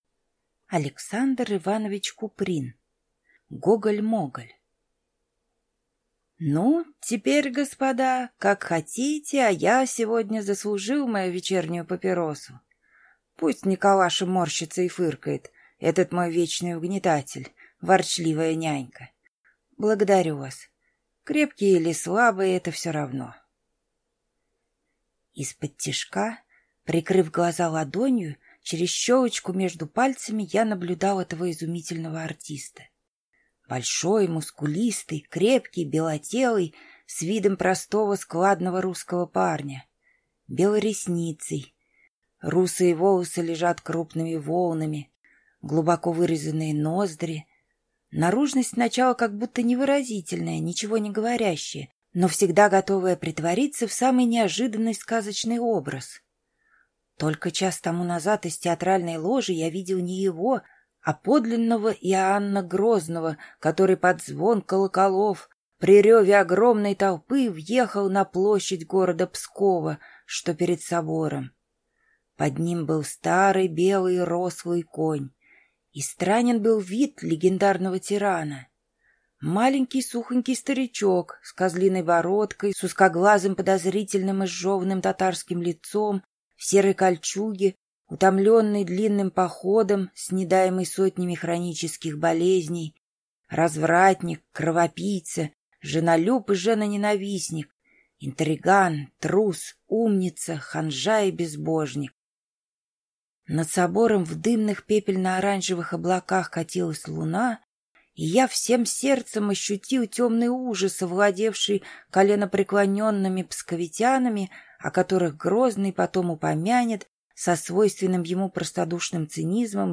Аудиокнига Гоголь-моголь | Библиотека аудиокниг